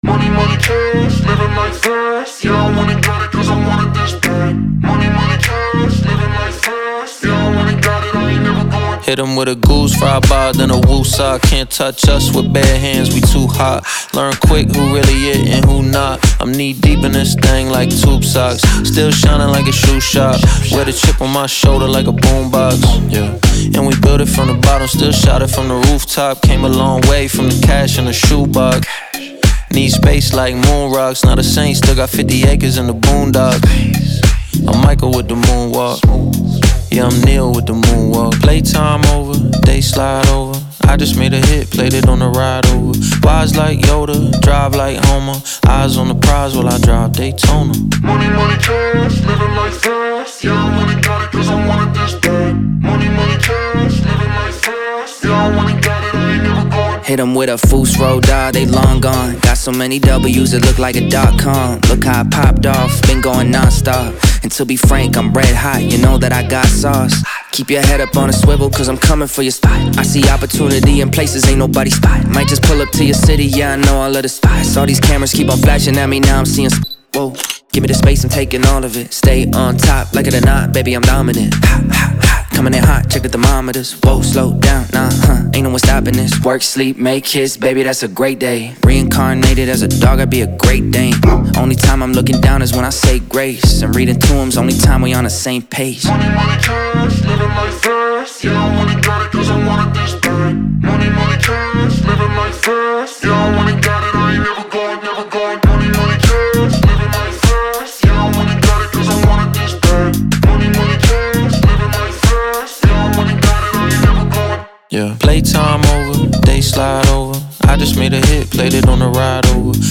BPM100-100
Audio QualityPerfect (High Quality)
Rap song for StepMania, ITGmania, Project Outfox
Full Length Song (not arcade length cut)